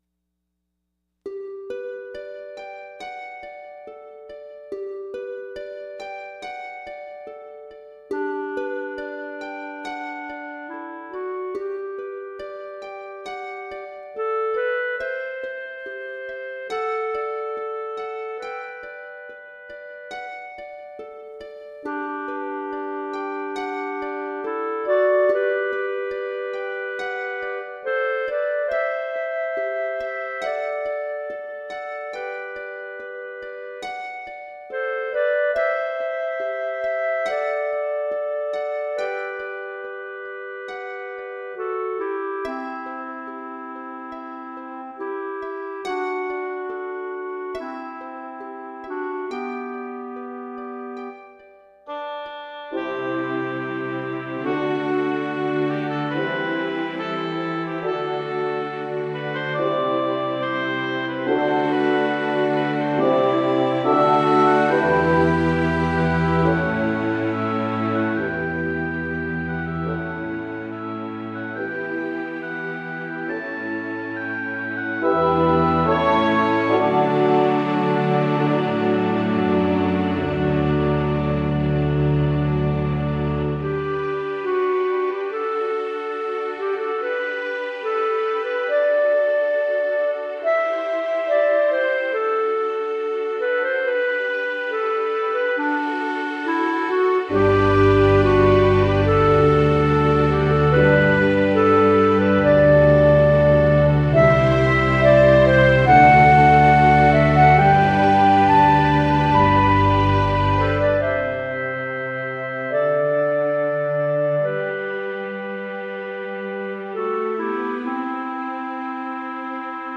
Thematic, instrumental/orchestral piece(Part 2 of 3)